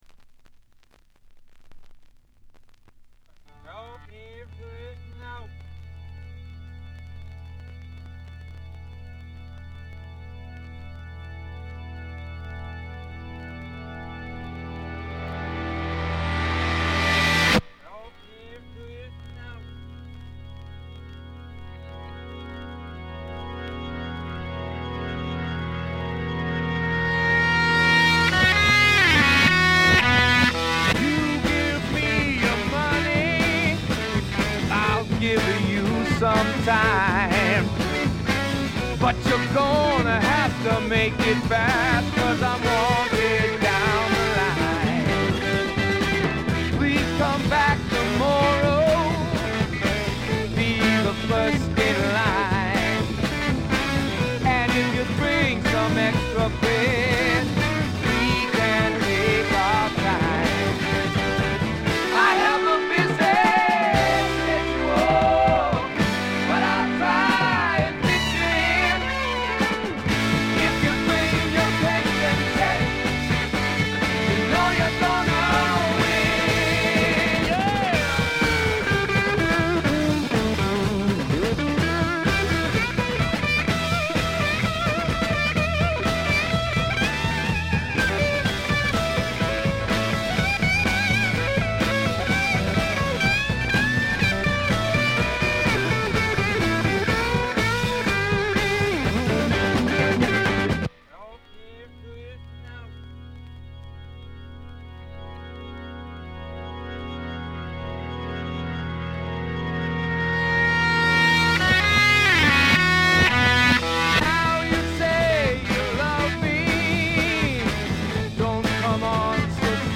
69年という最良の時代の最良のブルース・ロックを聴かせます。
試聴曲は現品からの取り込み音源です。